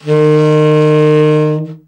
BRA_TEN SFT    6.wav